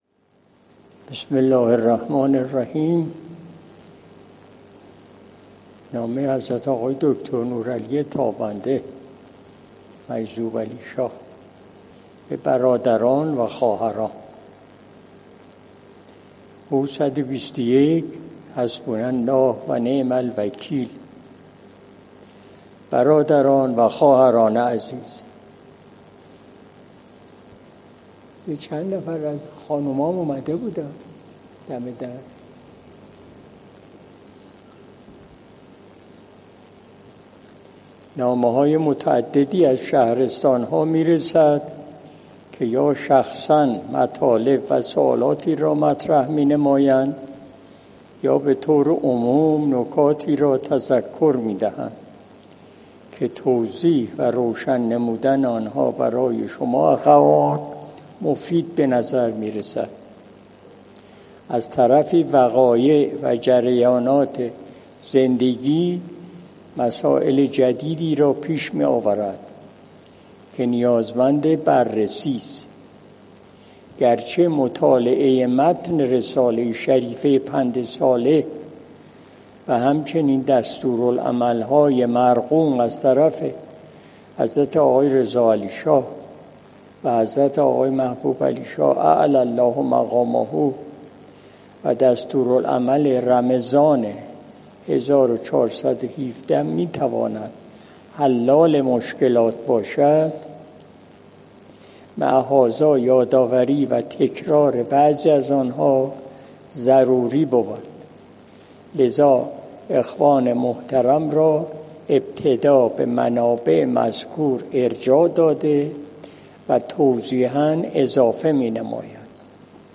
قرائت بیانیه‌های حضرت آقای مجذوبعلیشاه طاب‌ثراه: نامه به برادران و خواهران – ۱ مهر ماه ۱۳۷۶ – بیانیه پیرامون شأن والای ولایت و ولادت امام حسین(ع) – ۱۳ آذر ماه ۱۳۷۶